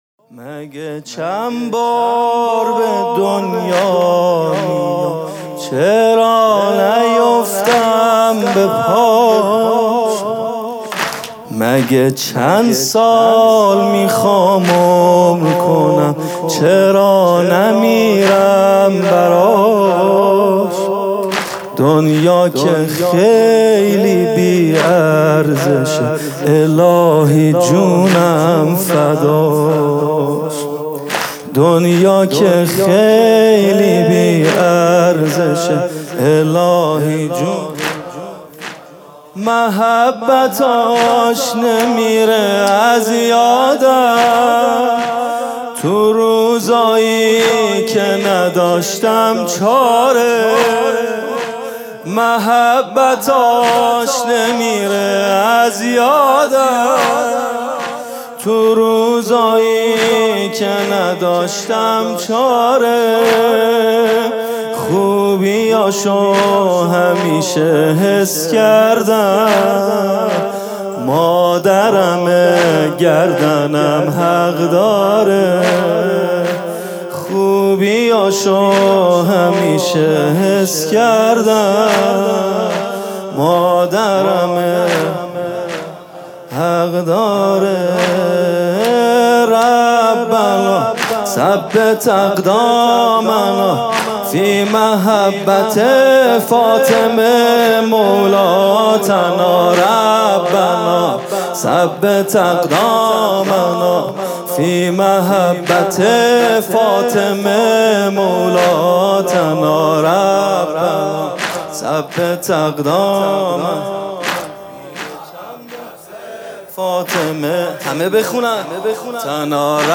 music-icon واحد: ربنا ثبت اقدامنا فی محبة فاطمه مولاتنا